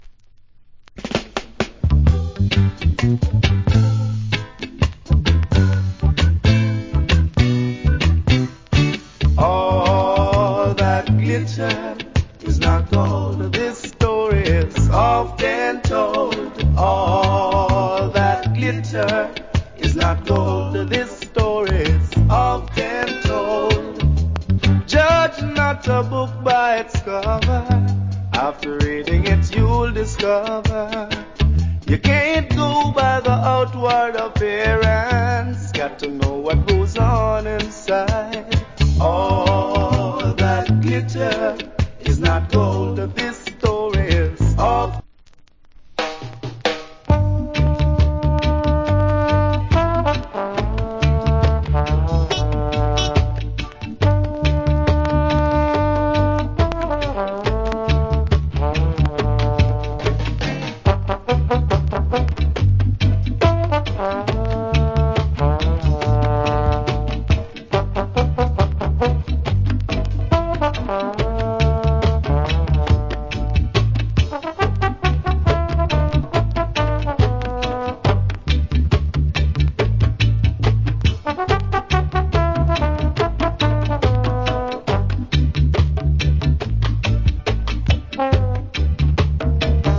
Reggae Vocal.